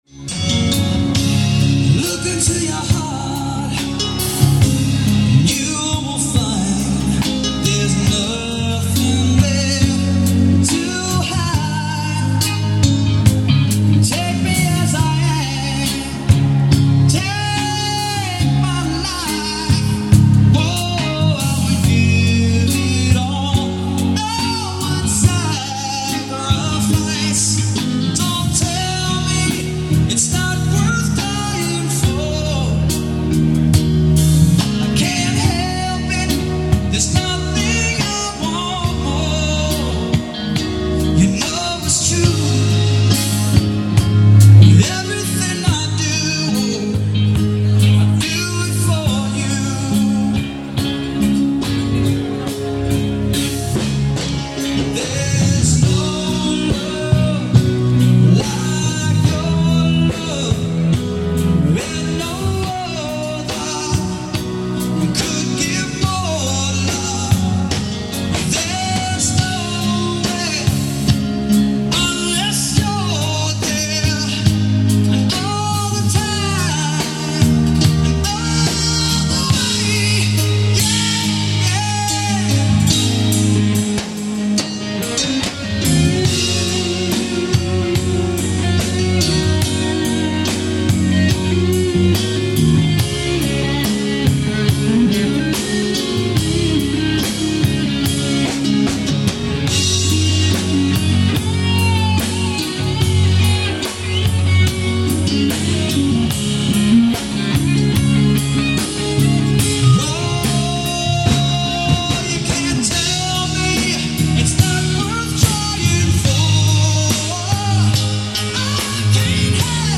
Some recordings may be clearer than others.
American Ballad